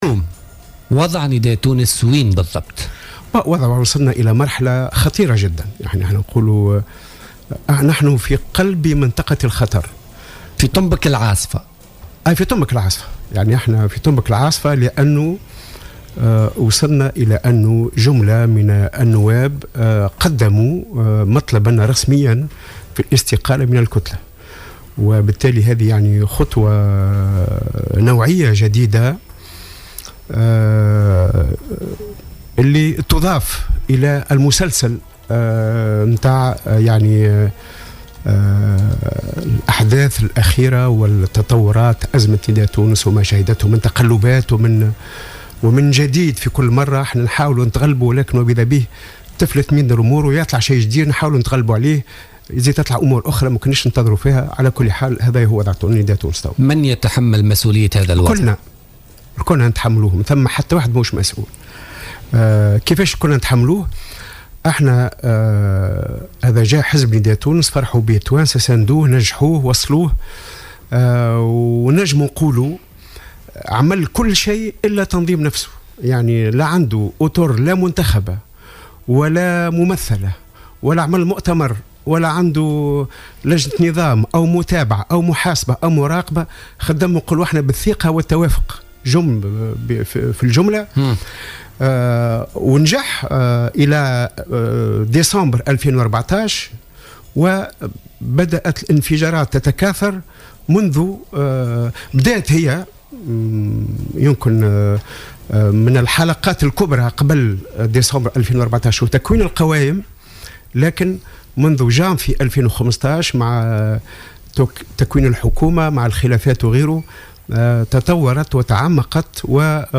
وقال الرميلي ضيف برنامج "بوليتيكا" اليوم إن نداء تونس وصل إلى مرحلة خطيرة و "أصبح في قلب العاصفة"، خاصة بعد استقالة مجموعة من نواب كتلته بمجلس نواب الشعب، محمّلا قيادات الحزب مسؤولية ما يحدث.